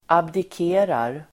Uttal: [abdik'e:rar]